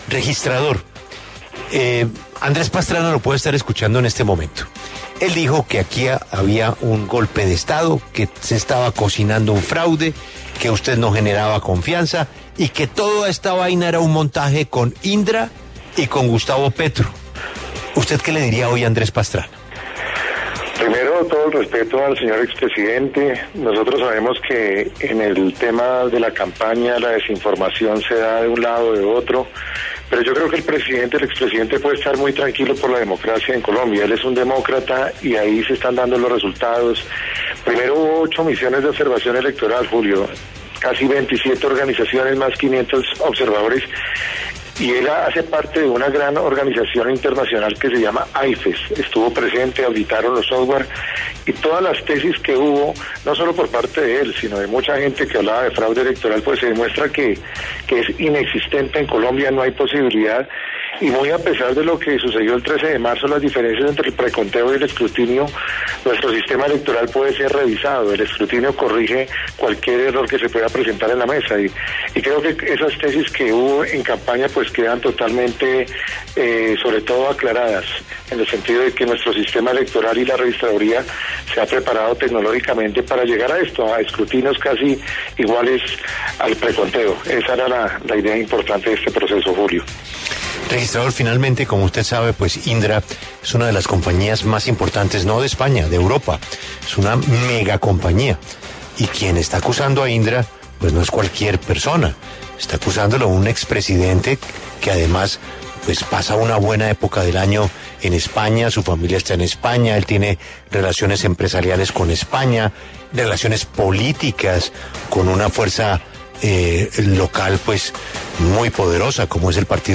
En entrevista con La W, el registrador Alexander Vega se refirió a las fuertes declaraciones que hizo el expresidente Andrés Pastrana, quien aseguró antes del 29 de mayo que se presentaría un golpe de estado y que la Registraduría no daba las garantías electorales.